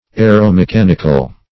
Definition of aeromechanical.
Search Result for " aeromechanical" : The Collaborative International Dictionary of English v.0.48: Aeromechanic \A`["e]r*o*me*chan"ic\, Aeromechanical \A`["e]r*o*me*chan"ical\, a. Of or pert. to a["e]romechanics.